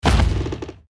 archer_skill_siegestance_02_foot.ogg